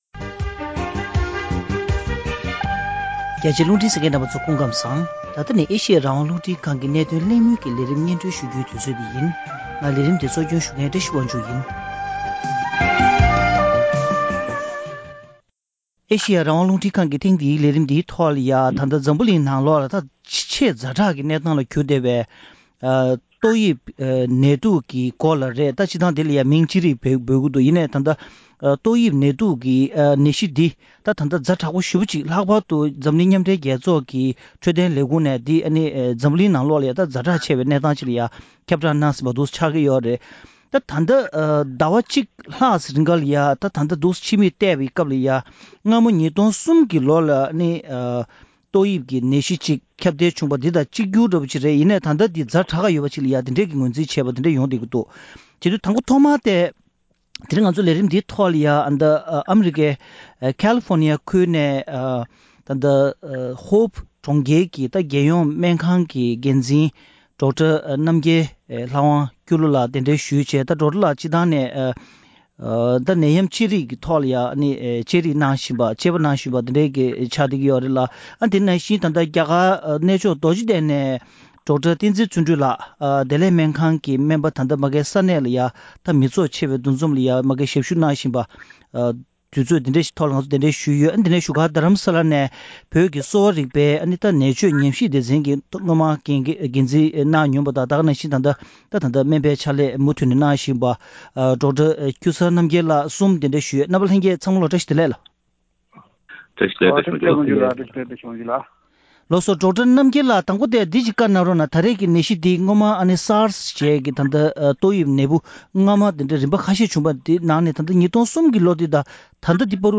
ཏོག་དབྱིབས་ནད་དུག་རྒྱ་ནག་གི་ཕྱི་ཕྱོགས་སུ་ཁྱབ་བཞིན་པ་དང་ཡོངས་ཁྱབ་རིམས་ནད་ཅིག་ཆགས་ཡོད་མེད་ཐད་གླེང་མོལ།